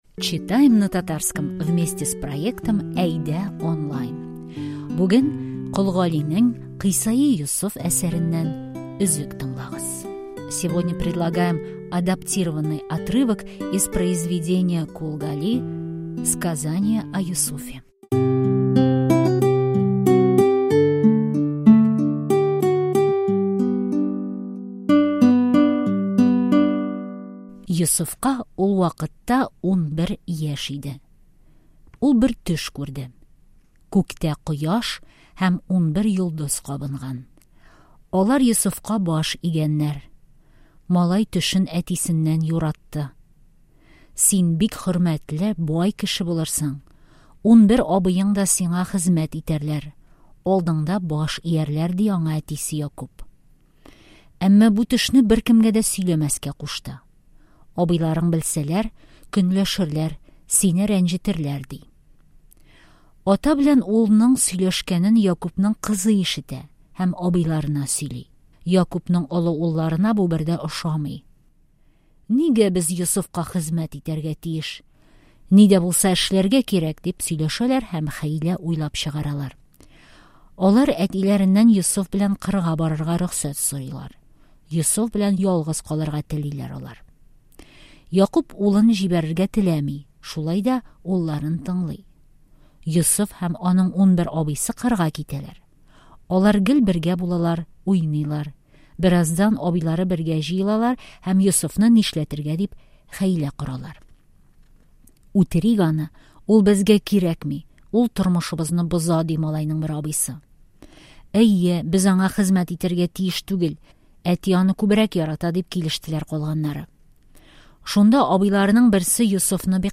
читаем на татарском